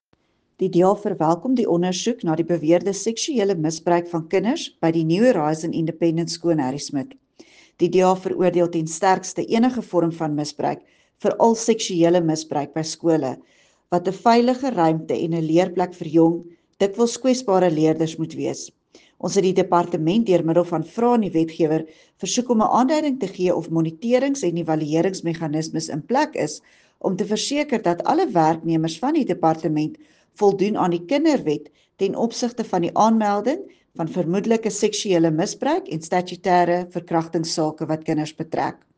Afrikaans soundbites by Dulandi Leech MPL and